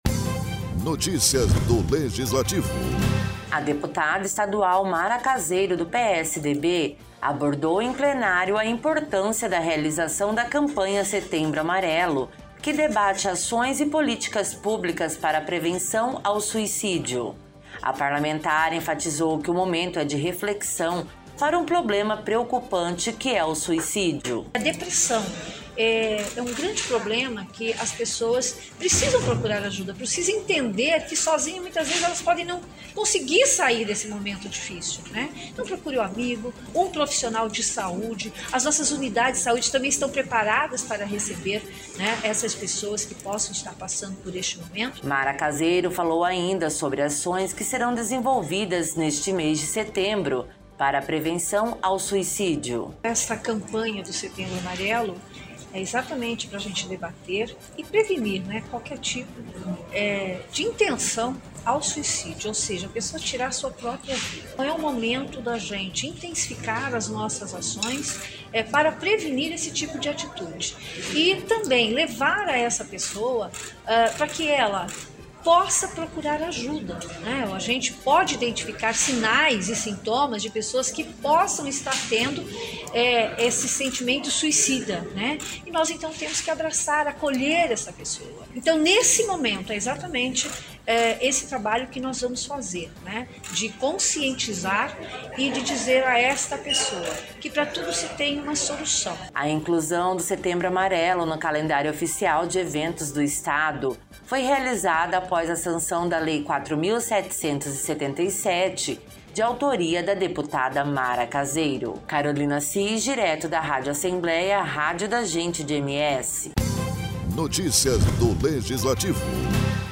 Durante a sessão plenária desta quarta-feira (01), a deputada estadual Mara Caseiro abordou em plenário a importância da realização da campanha Setembro Amarelo que debate ações e políticas para prevenção ao suicídio.